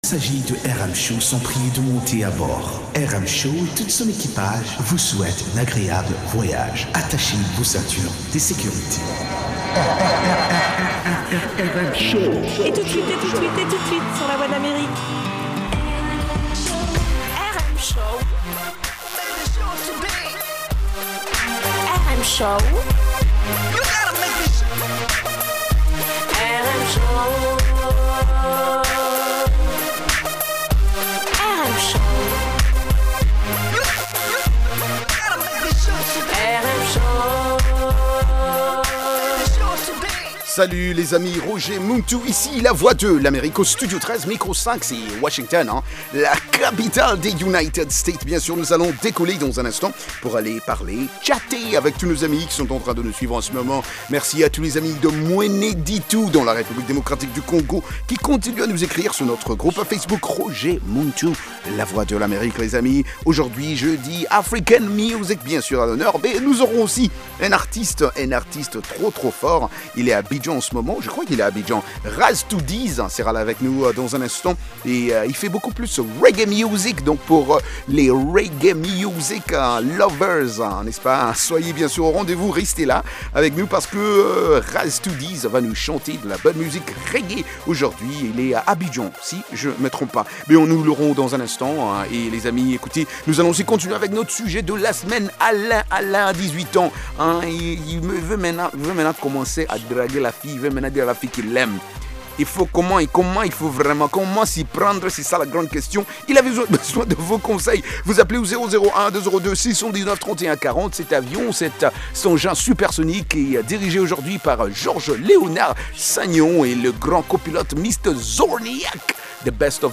propose notamment d'écouter de la musique africaine, des articles sur l'actualité Afro Music, des reportages et interviews sur des événements et spectacles africains aux USA ou en Afrique.